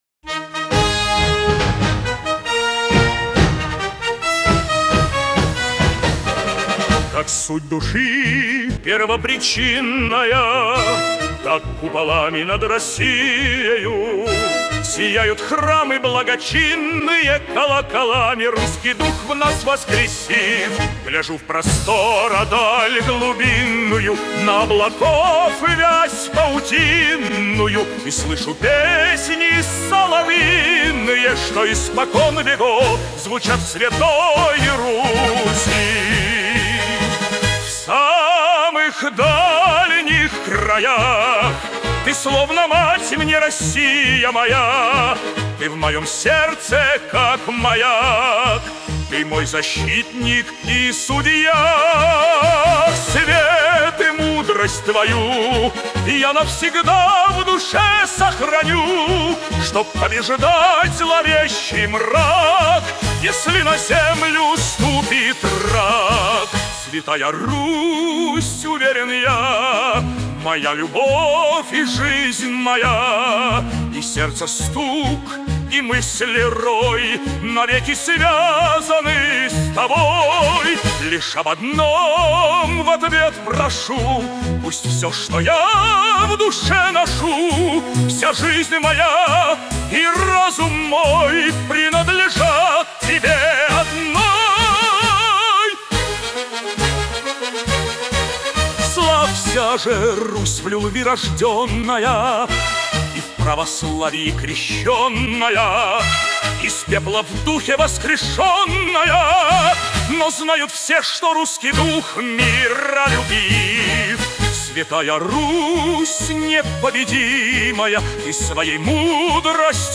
Вариант 2 (марш)